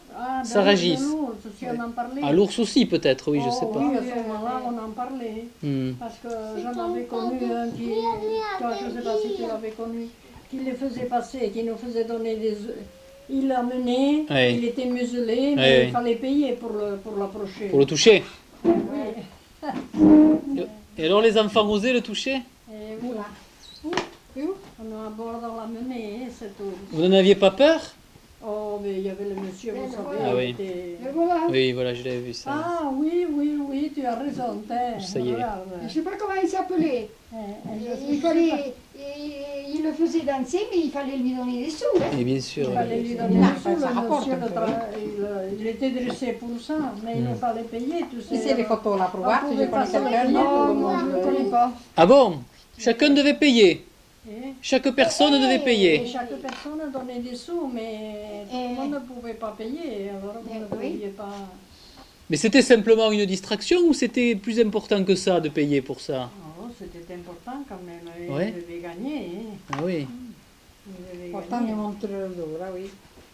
Lieu : Montjoie-en-Couserans
Genre : témoignage thématique